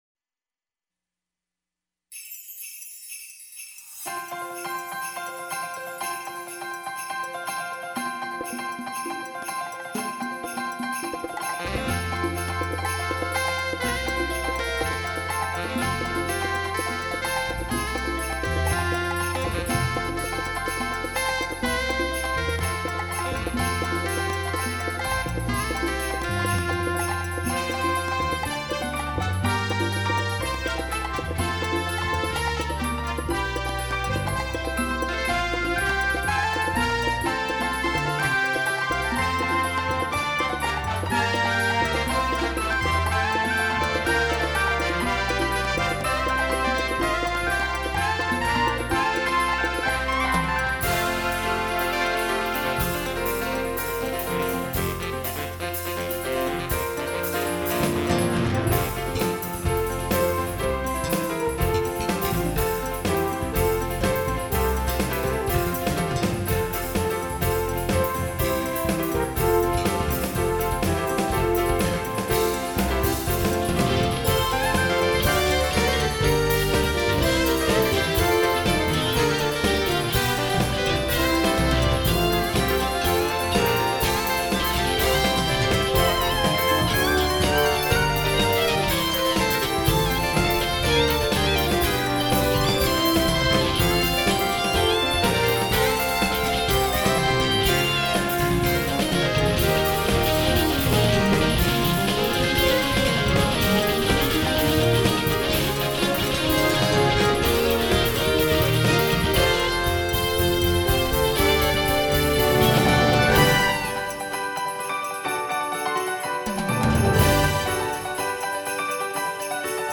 This meant that if you learned the piano, you really could make it 'sound' like you could play ANYTHING because of the keyboard sounds!
EVERY instrument on this recording is me (on the keyboard), even the drums and electric guitar!